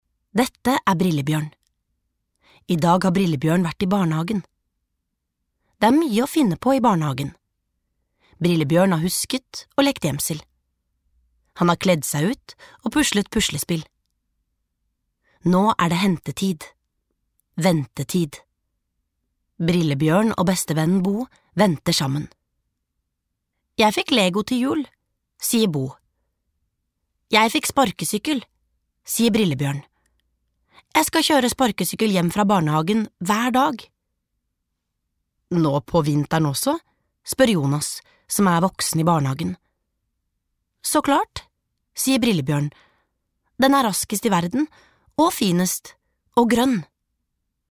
Nedlastbar lydbok